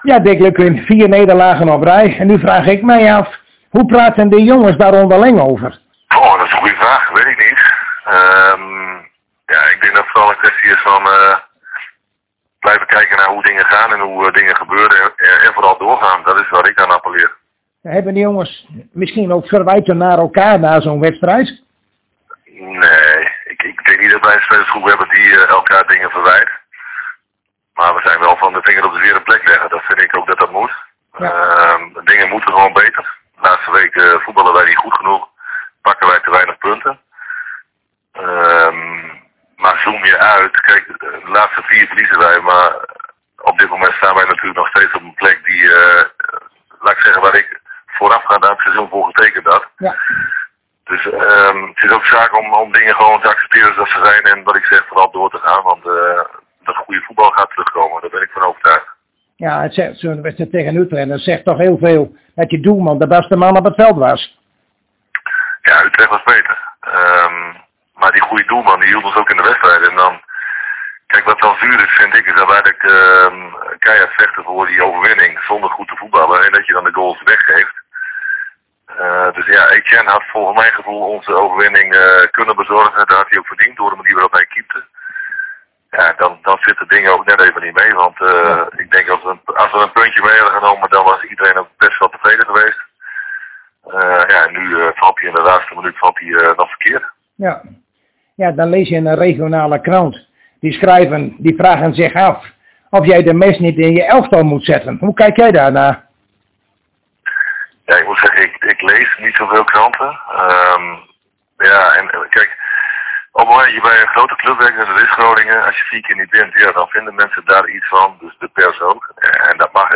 In gesprek met Dick Lukkien
Zojuist spraken wij weer met trainer Dick Lukkien en dat is hier te beluisteren.